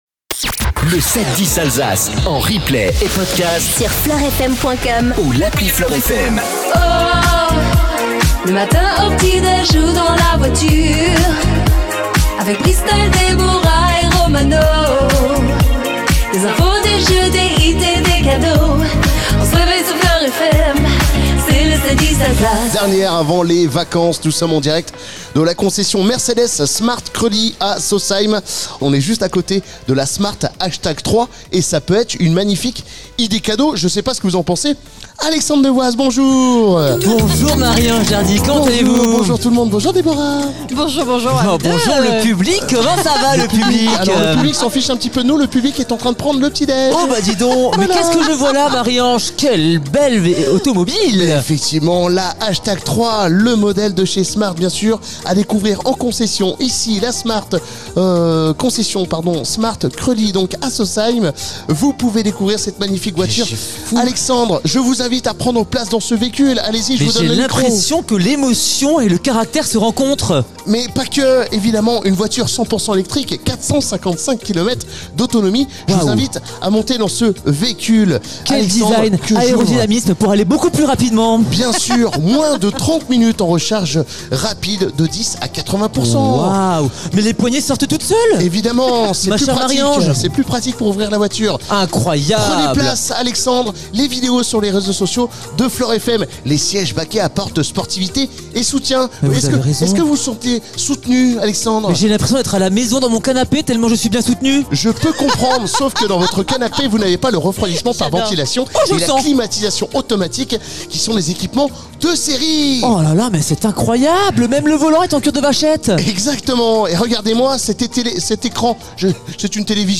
PODCAST 710 ALSACE M2COLOR FLOR FM Vendredi 20 décembre 0:00 25 min 50 sec 20 décembre 2024 - 25 min 50 sec LE 7-10 DU 20 DECEMBRE Retrouvez les meilleurs moments du 7-10 Alsace avec M2 Color , votre façadier dans le Haut-Rhin, la dernière pour 2024, en direct de la concession SMART KROELY MULHOUSE.